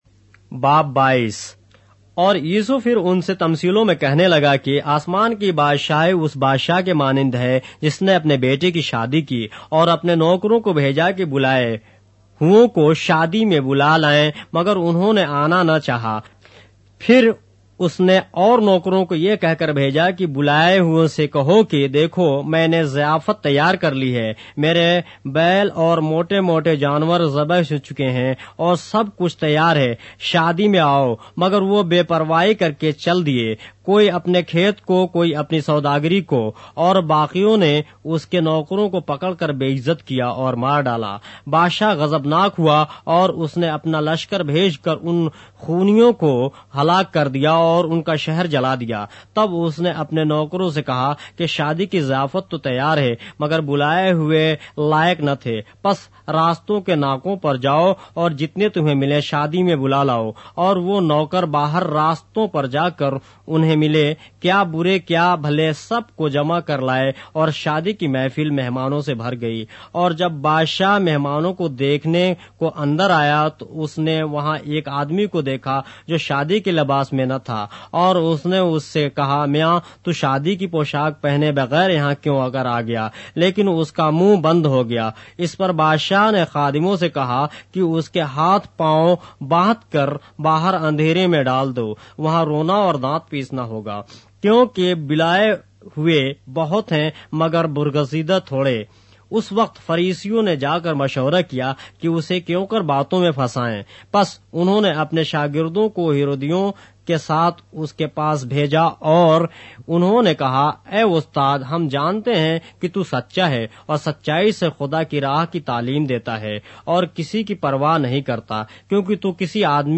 اردو بائبل کے باب - آڈیو روایت کے ساتھ - Matthew, chapter 22 of the Holy Bible in Urdu